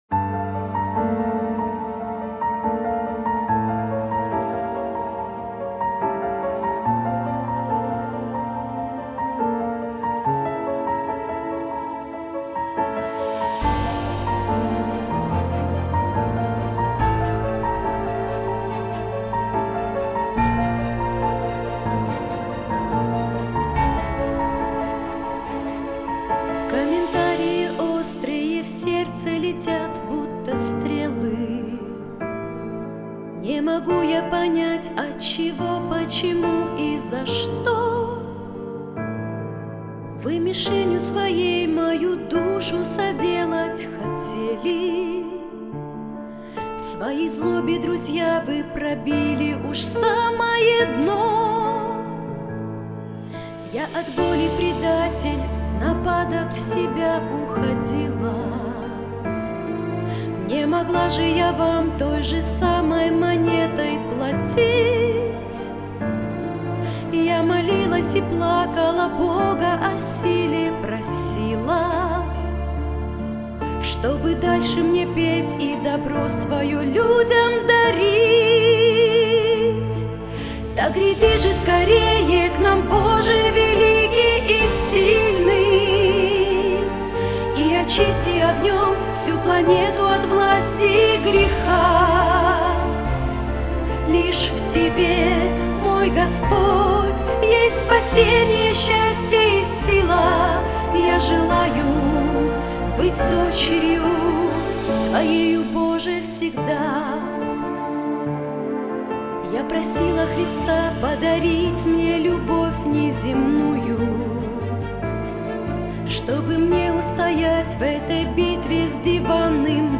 песня
125 просмотров 174 прослушивания 23 скачивания BPM: 98